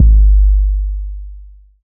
DDWV 808 1.wav